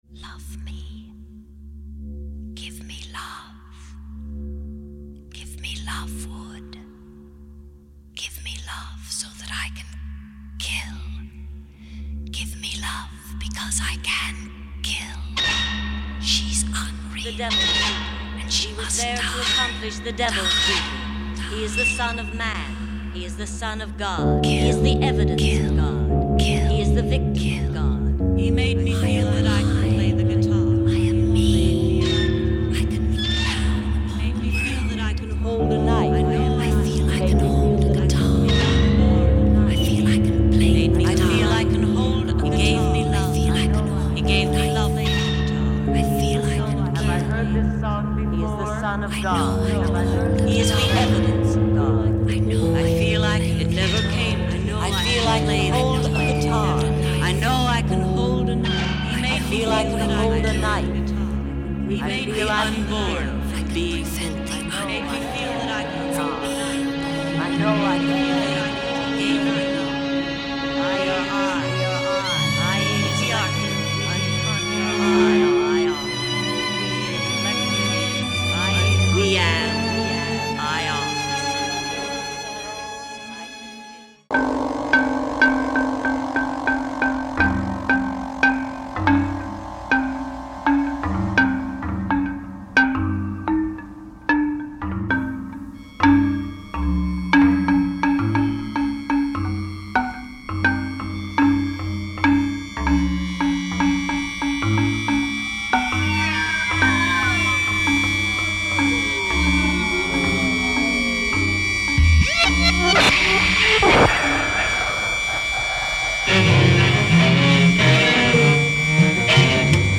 trumpet
piano